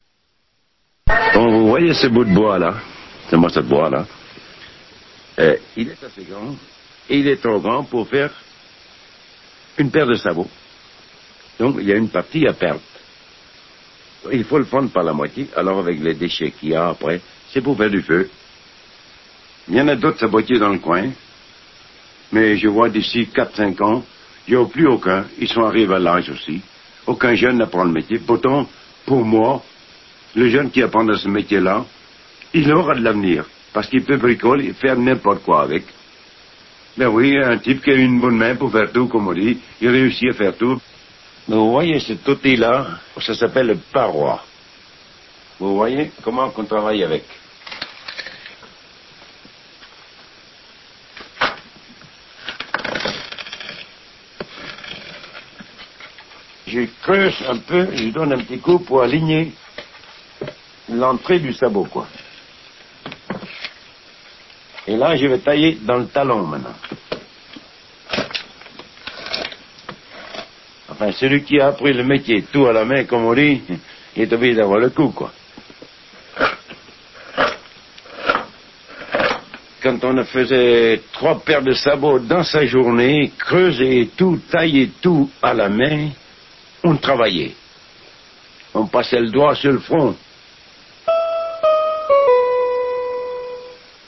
bretagne1_rm.mp3